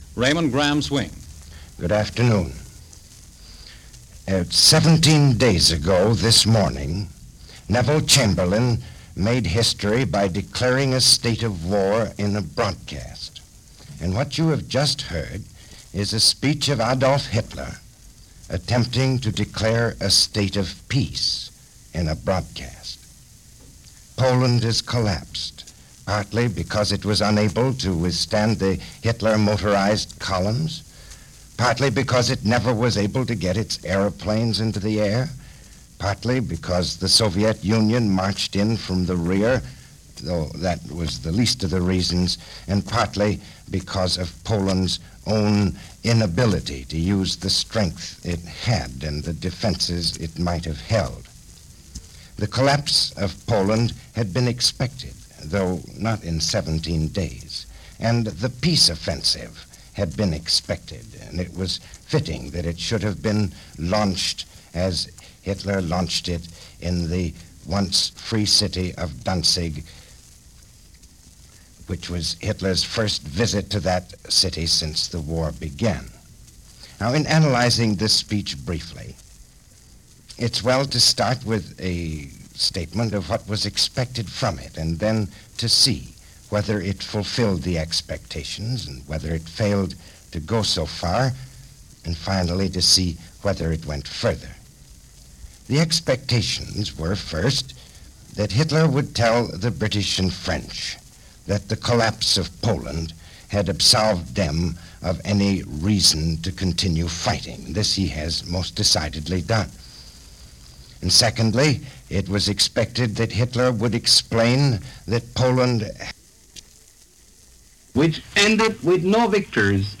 September 19, 1939 - War In Europe: Day 17 - Attacks, Advances, Denials - Charges and Counter-Charges - News for the events of this day in 1939.